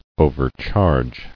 [o·ver·charge]